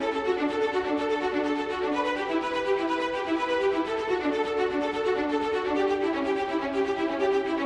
弦乐 52 125 Bpm
Tag: 125 bpm Cinematic Loops Strings Loops 1.29 MB wav Key : Unknown